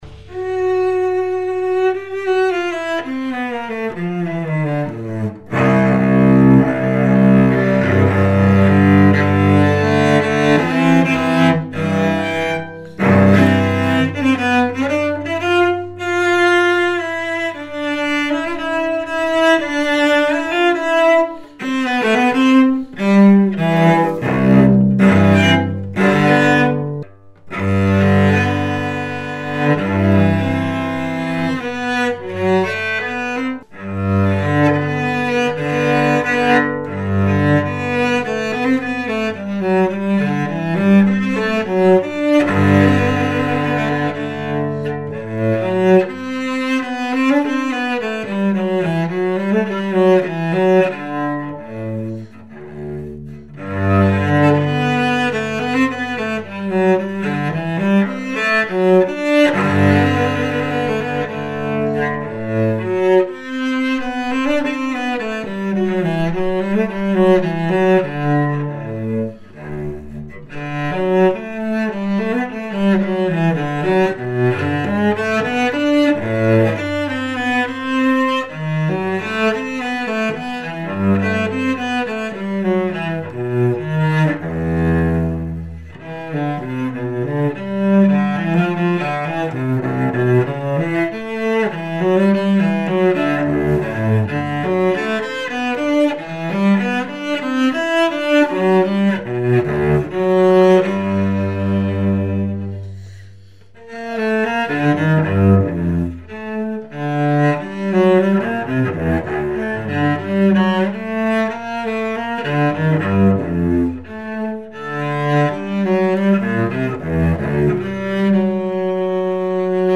演奏者紹介)さんをお迎えし、試奏と以下のそれぞれのチェロの講評をして頂きました。
珍しいワンピースバックのチェロです。鳴ります！！